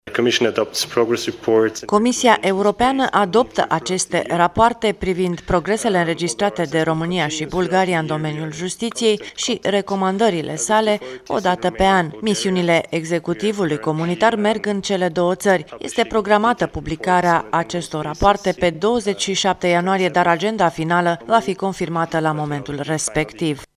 Rapoartele pe Justiţie ale Comisiei Europene din cadrul Mecanismului de Cooperare şi Verificare urmează să fie date publicităţii pe 27 ianuarie; eventuale schimbări ale datei vor fi comunicate. Anunţul a fost făcut azi la conferinţa de presă a Comisiei